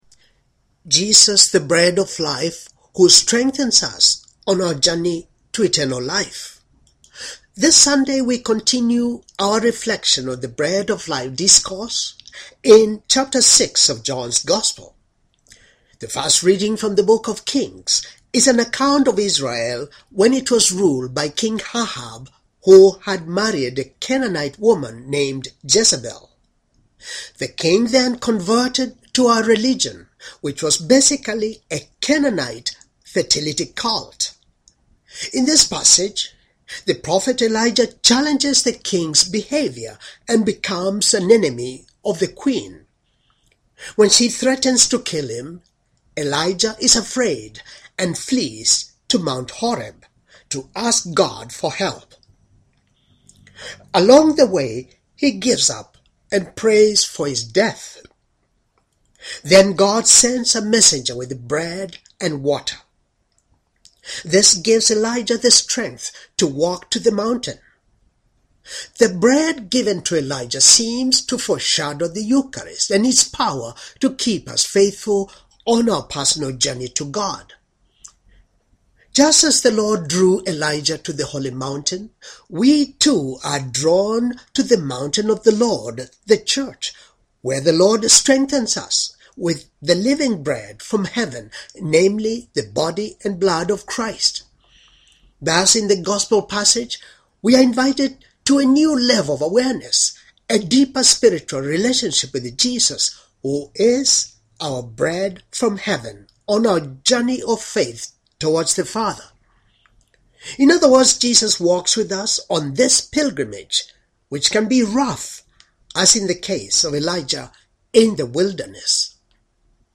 Homily for Nineteenth Sunday in Ordinary Time, Year B